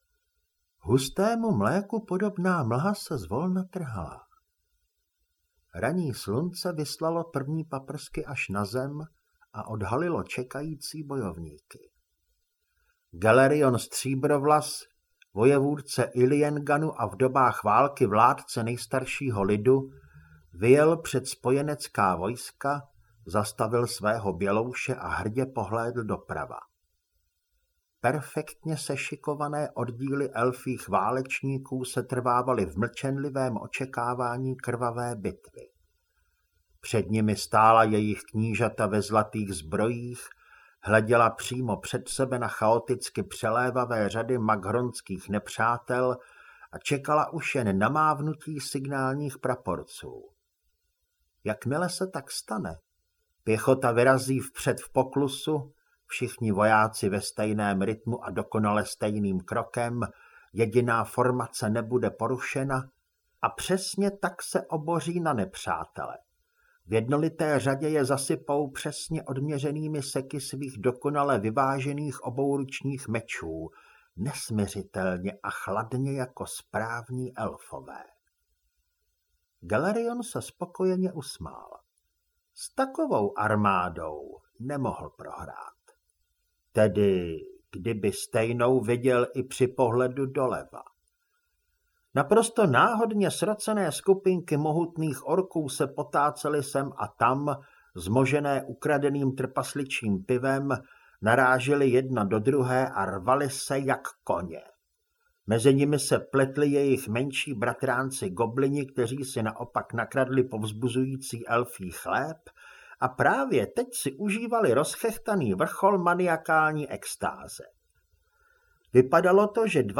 Zelená audiokniha
Ukázka z knihy